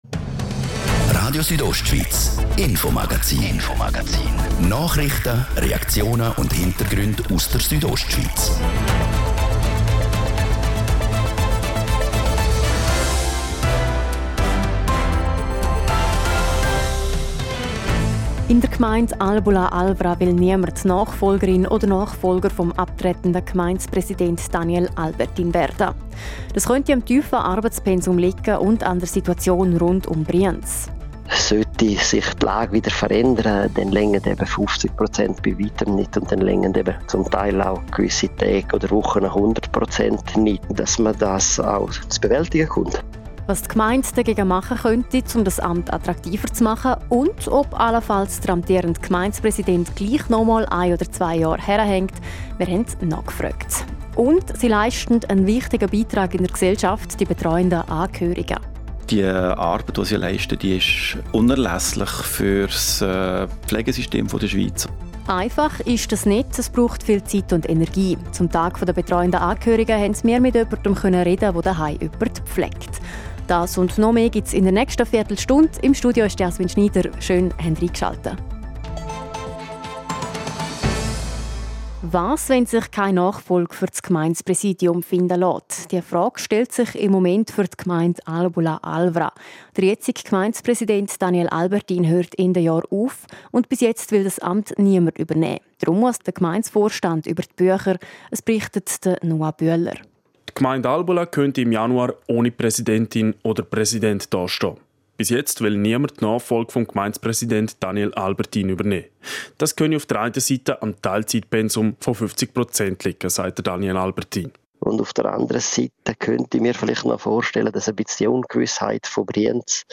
Eine Pflegende erzählt von ihrem Engagement.